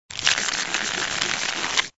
SA_rolodex.ogg